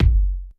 keys_27.wav